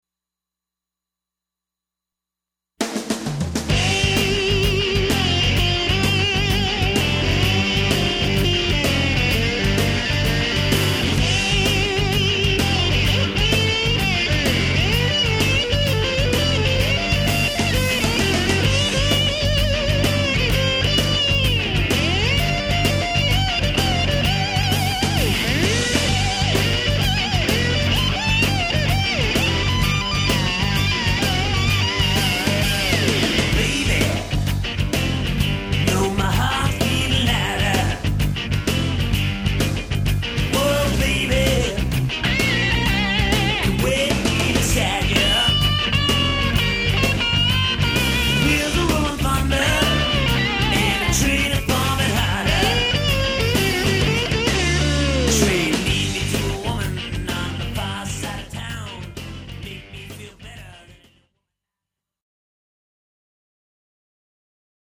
searing extended solo trade-offs